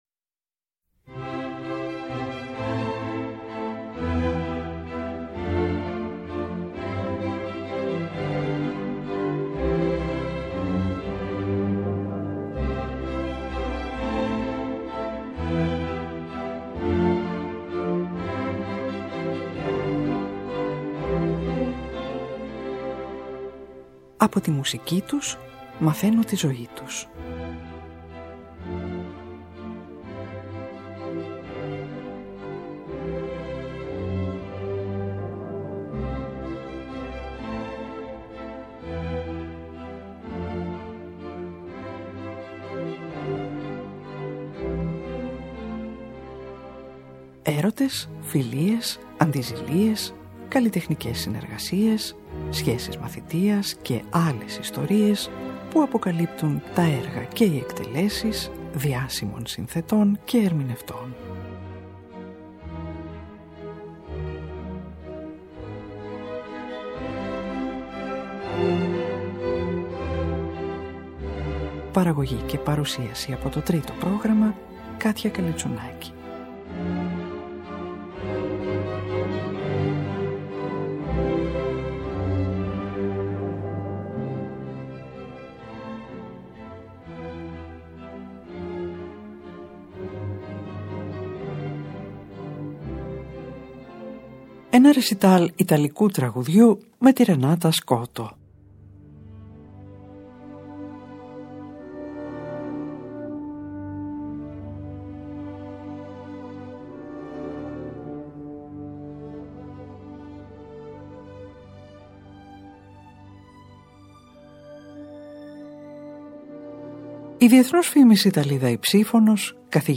Ιταλίδα υψίφωνος
πιανίστα